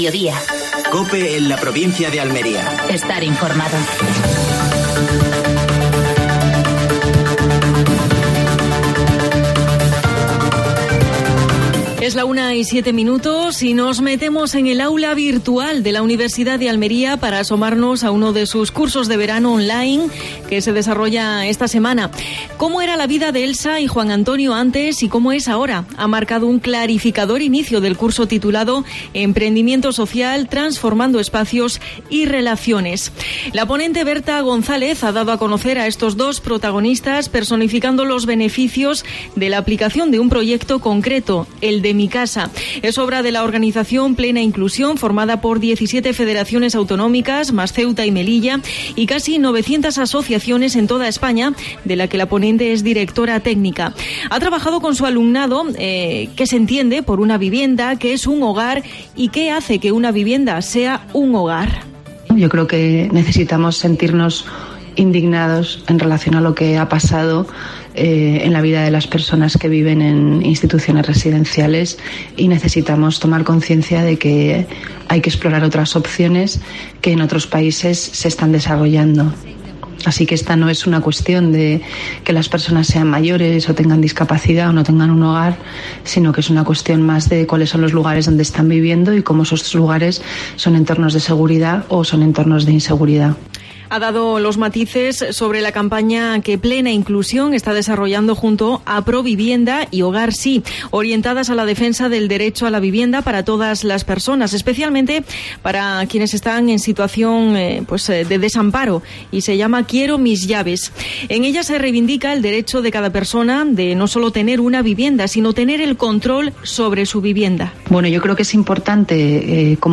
AUDIO: Actualidad en Almería. Entrevista a Javier A. García (presidente de la Diputación Provincial de Almería), al cumplirse un año de este mandato.